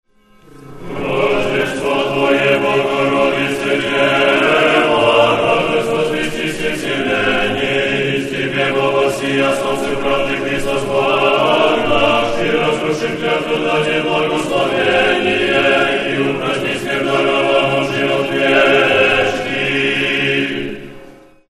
Тропарь Рождества Пресвятой Богородицы